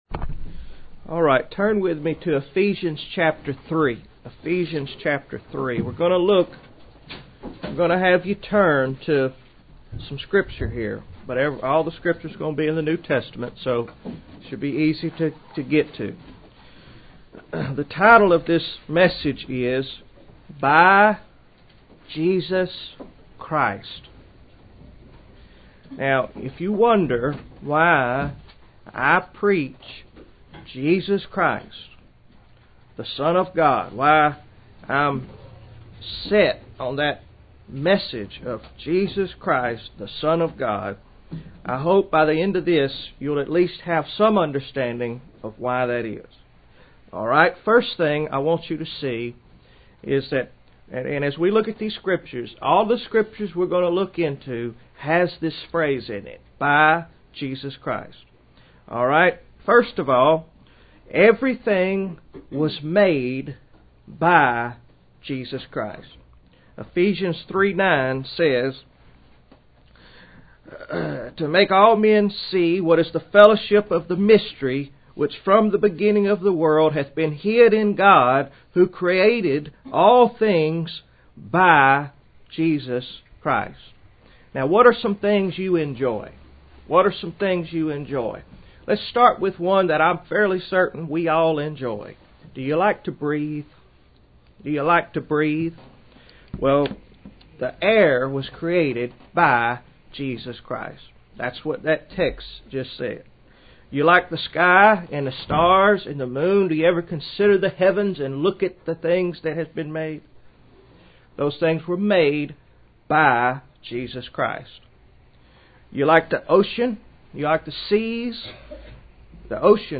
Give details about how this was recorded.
Title: By Jesus Christ Text: Various Date: April 25, 2010 Place: Sovereign Grace Baptist Church , Princeton , New Jersey The title of our message today is "By Jesus Christ."